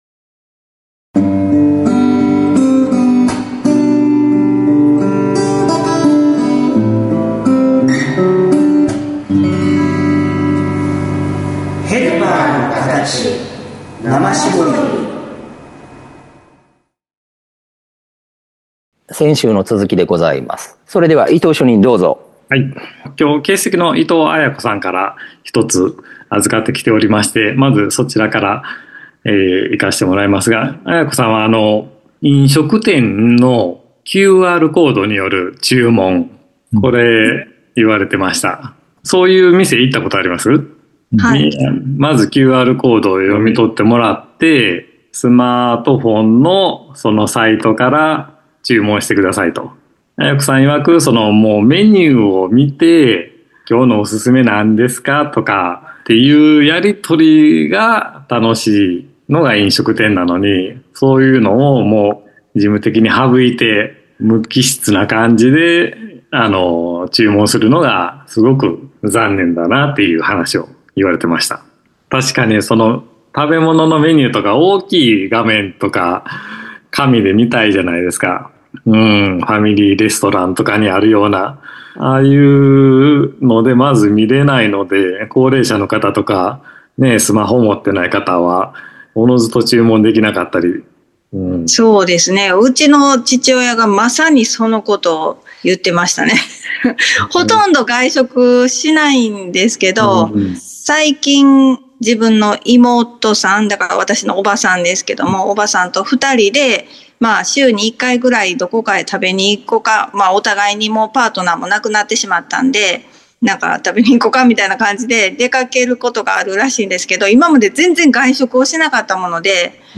＜今週のテーマ＞ 先週に引き続きまして、 テクノロジー進化による 功罪についての談論を 配信致します。